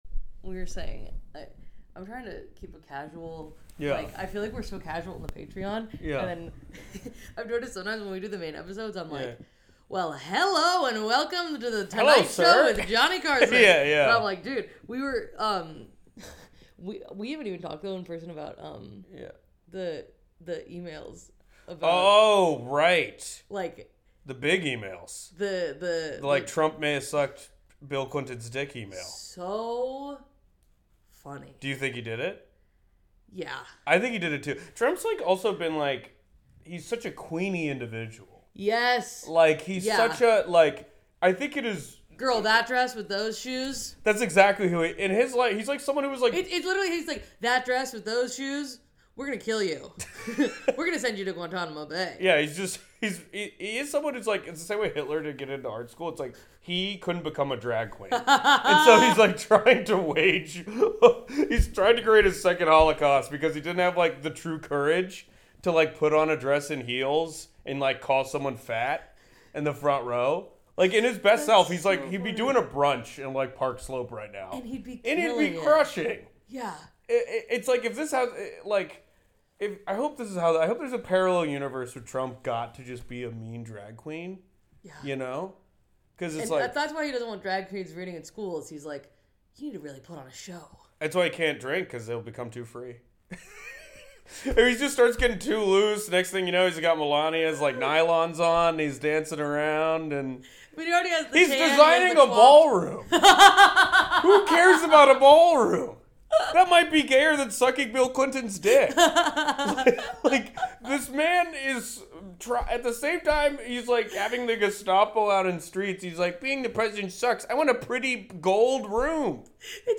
A comedy podcast for lunatics.